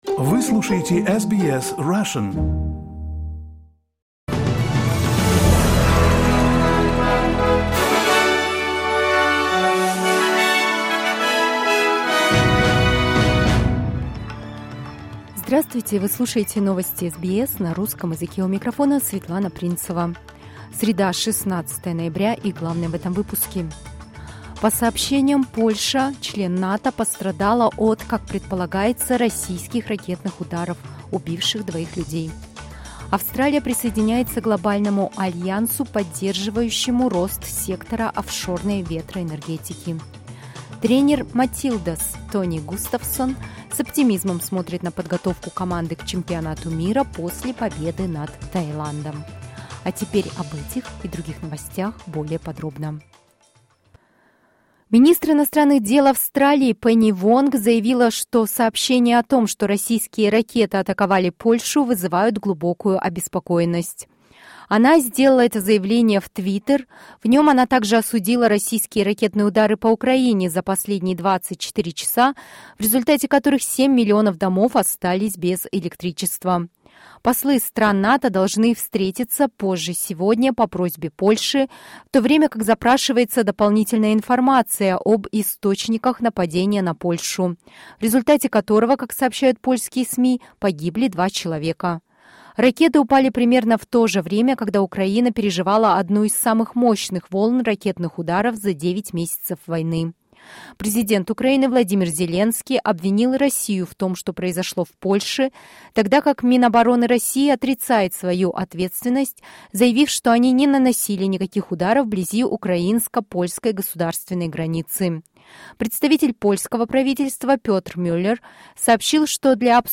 SBS news in Russian - 16.11.22